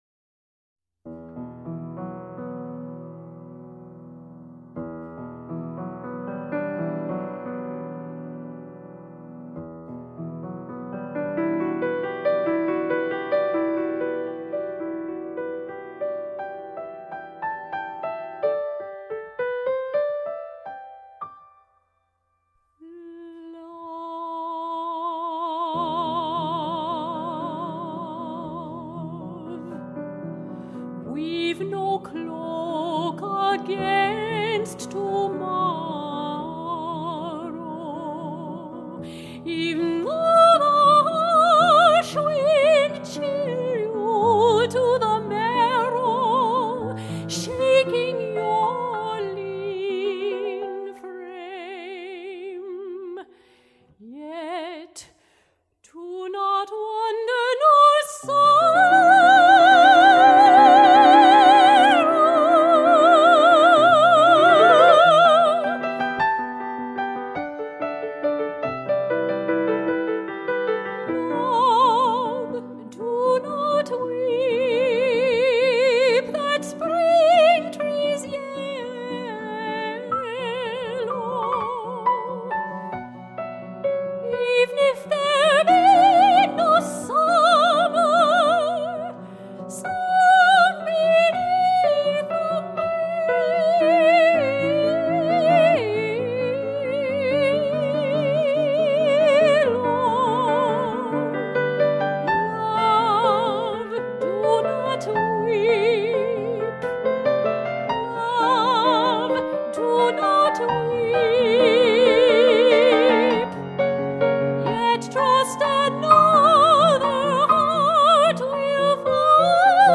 for Voice and Piano (1974, rev. 1986)